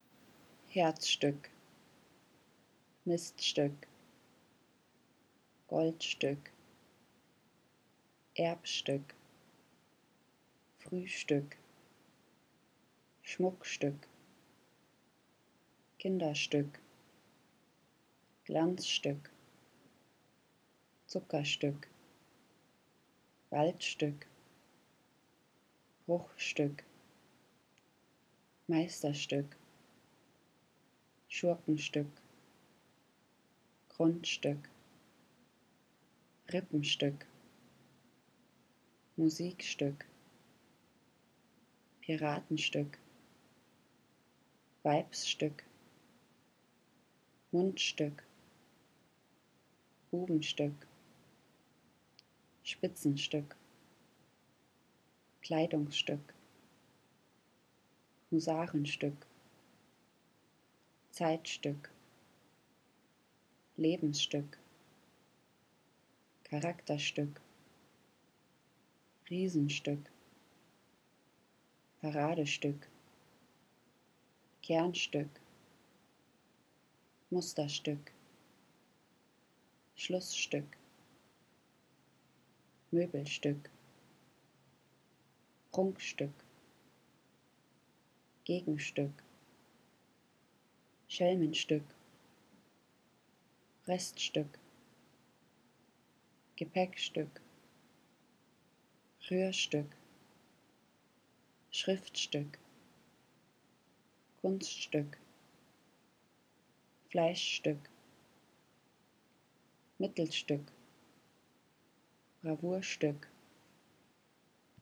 Die gebetsmühlenartige Wiederholung der gleichen Wortendungen greift den Herzrhythmus auf.
As in music, the eardrum begins to oscillate in time to the beat of the repeated words – a drummer in the ear, so to speak.
The sound of cascading words with identical endings can put a sensitive audience into a kind of trance.